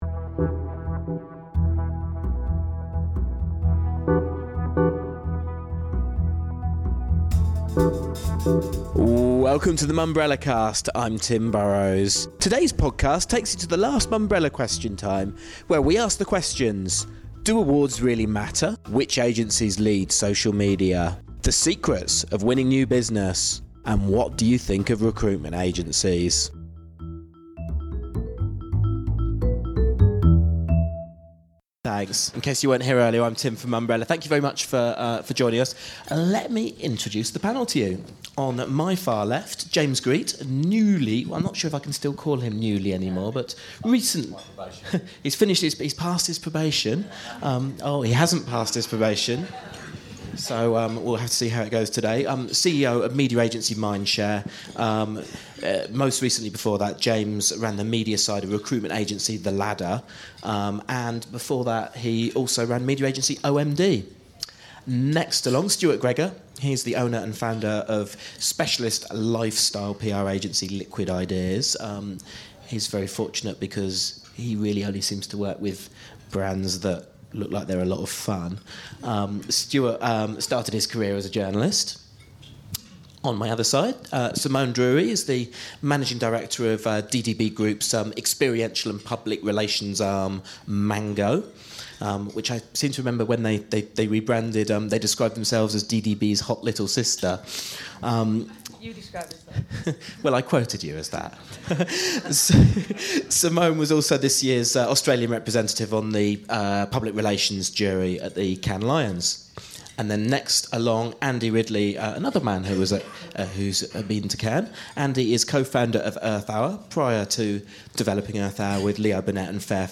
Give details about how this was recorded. In this weeks Mumbrellacast:Our most recent Mumbrella Question Time breakfast including: Do awards really matter?